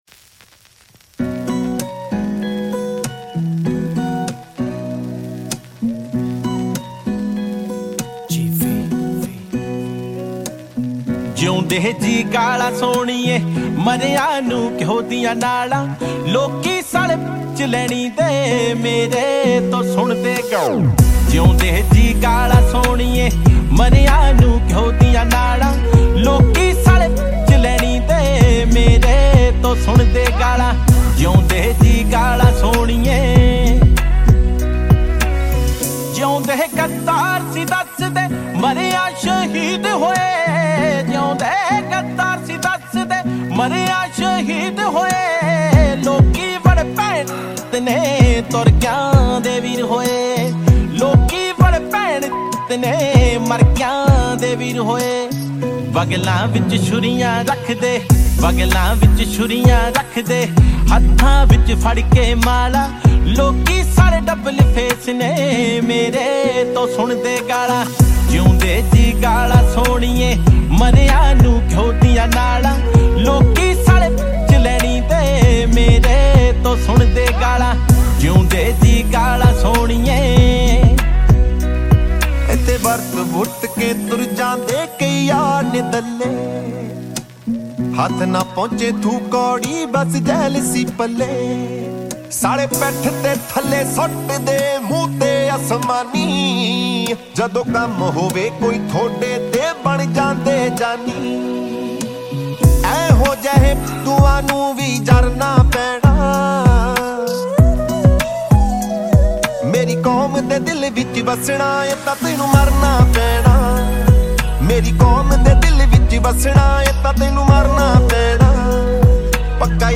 Category New Punjabi Song 2023 Singer(s